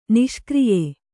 ♪ niṣkriye